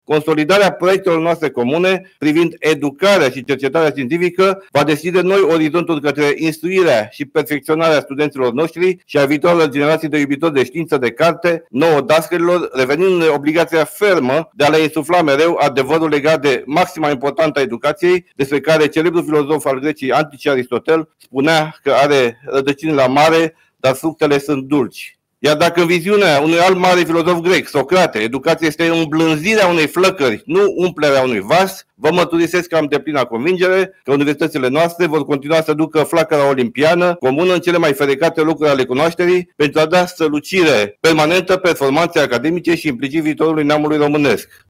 La ceremonia de conferire a titlului au participat cadre universitare, studenți și invitați.